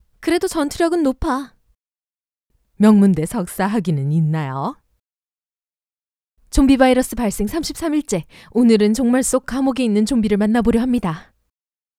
推荐-游戏角色